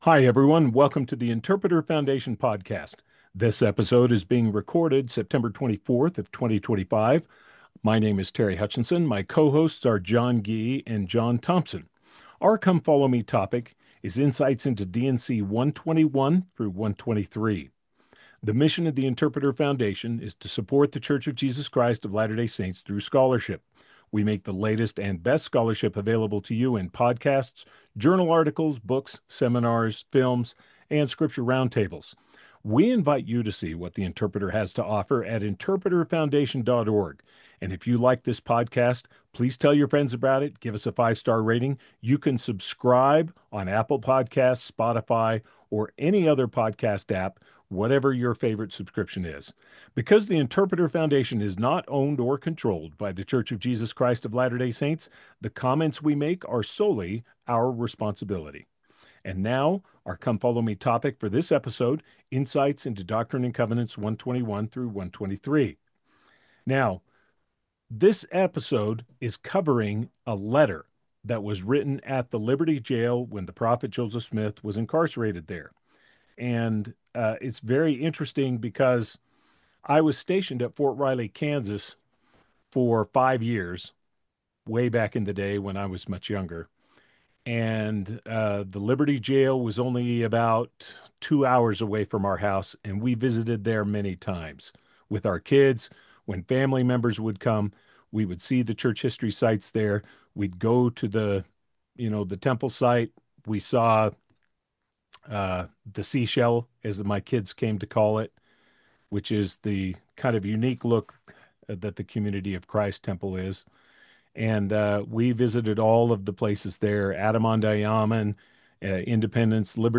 Podcast: Download The Interpreter Foundation Podcast is a weekly discussion of matters of interest to the hosts and guests.